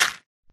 Sound / Minecraft / dig / gravel4